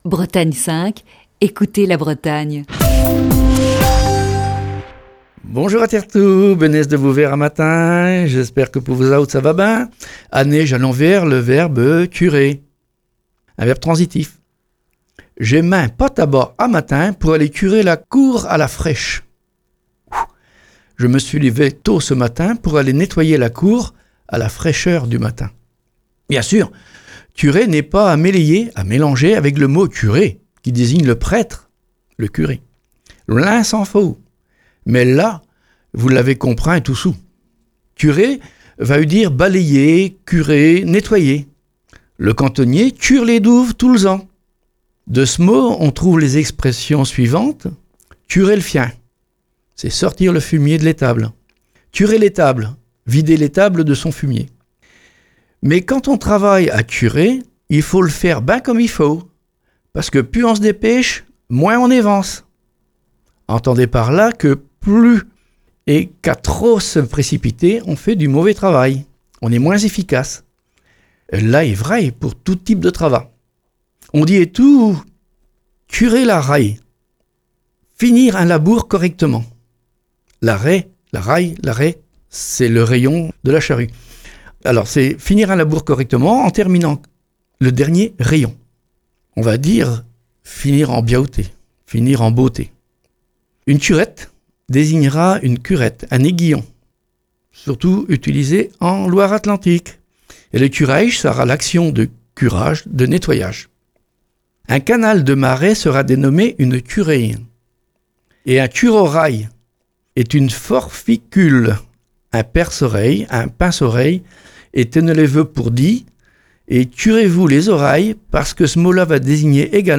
Chronique du 8 avril 2020.